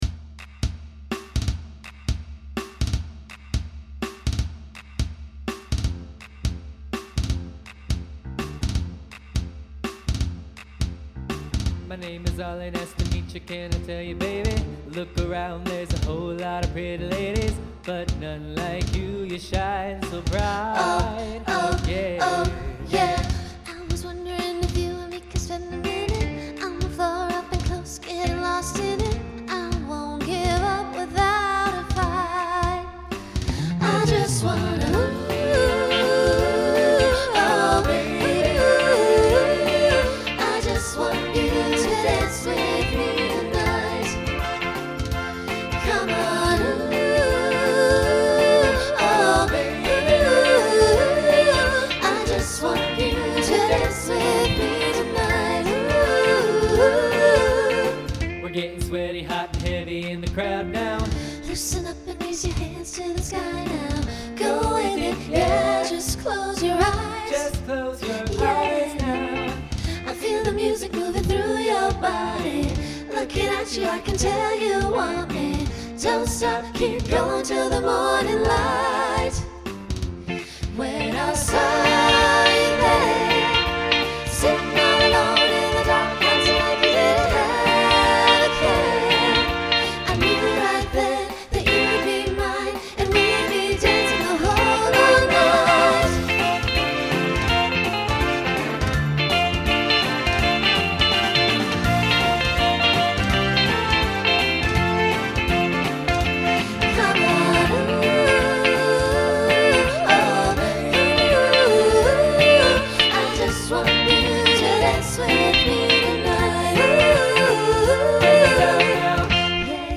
Voicing SATB Instrumental combo Genre Pop/Dance , Swing/Jazz